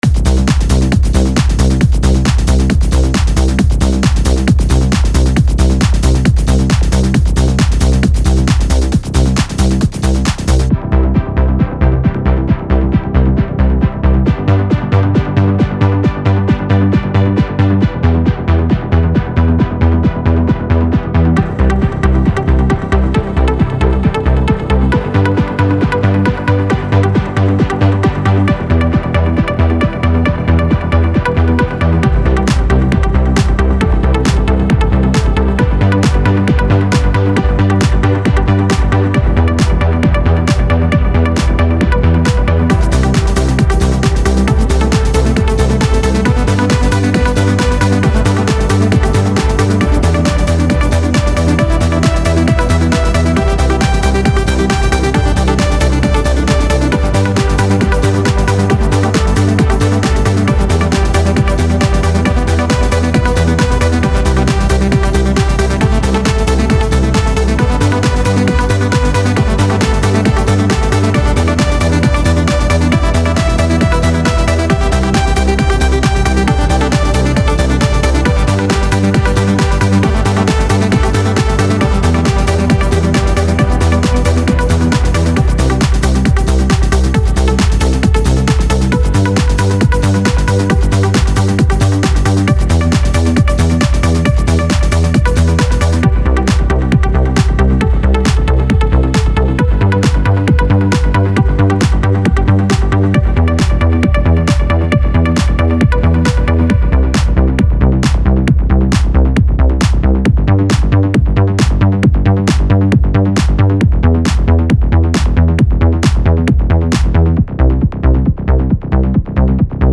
Some sorta soft trance WIP2